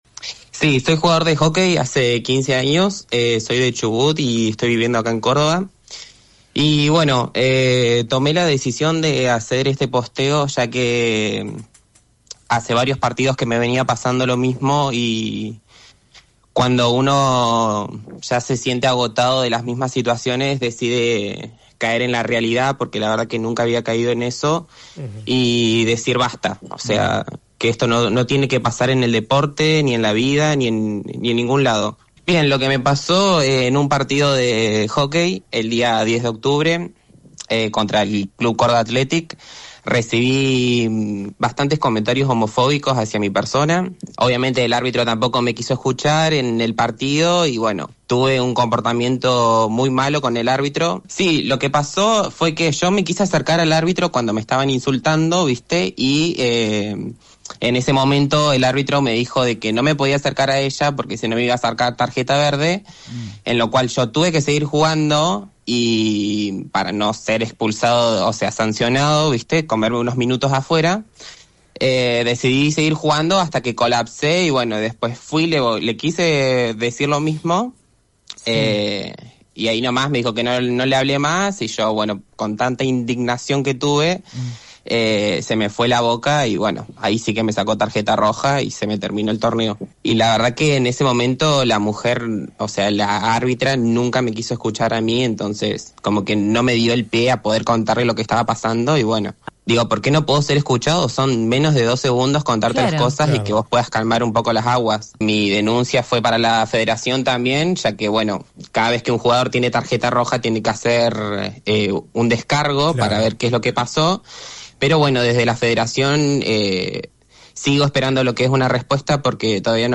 «Recibí bastantes comentarios homofóbicos hacia mi persona, obviamente el árbitro tampoco me quiso escuchar en el partido y bueno, de tanta injusticia y de tantas cosas de aguantar tanto, colapsé», relató el deportista en diálogo con La 10 Córdoba FM 98.7.